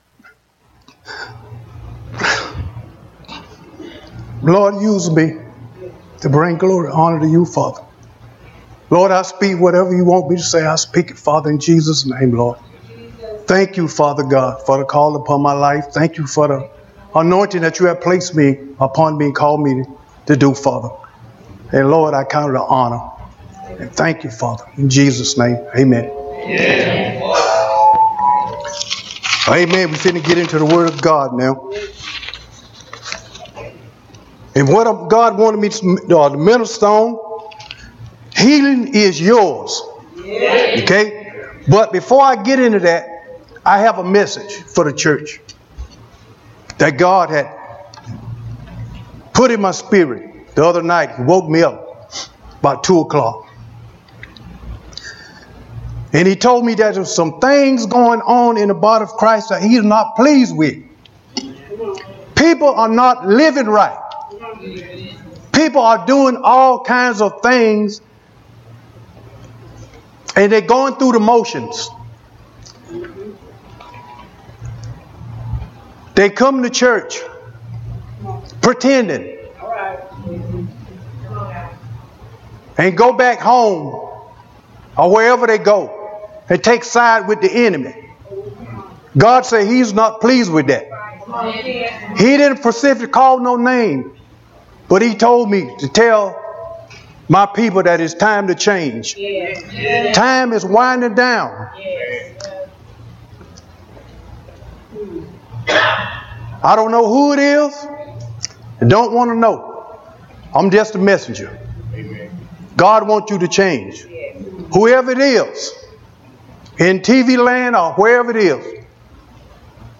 Sermons - Word of Life Fellowship Church - Page 2